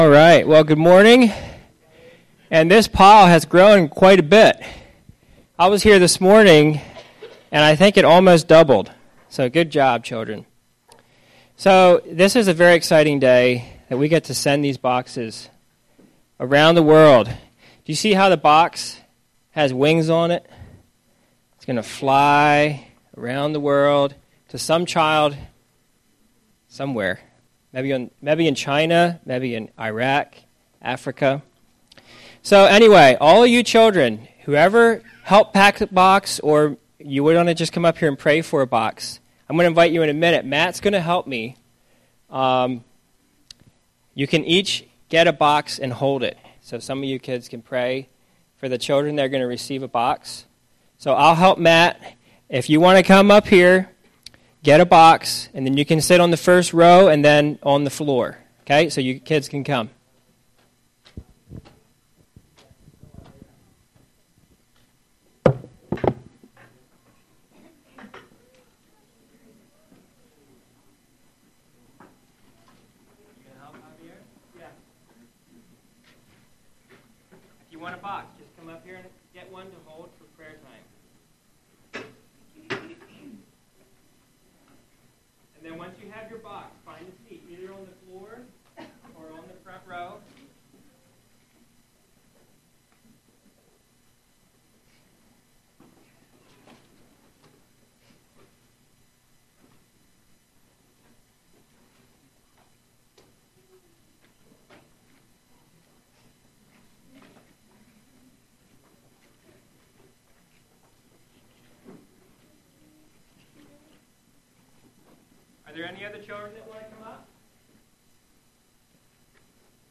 Thanksgiving_Service.mp3